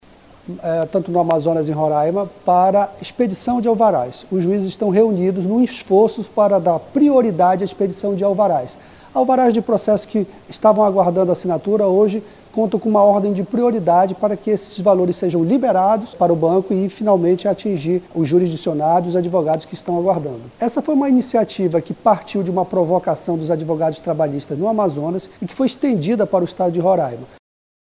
A medida busca agilizar a liberação de créditos trabalhistas, resolver pendências de alvarás preparados e dar maior rapidez às decisões judiciais, como destaca o juiz do Trabalho, Ney Rocha.